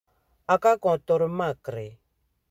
Lecture et prononciation
Lisez les phrases suivantes à haute voix, puis cliquez sur l'audio pour savoir si votre prononciation est la bonne.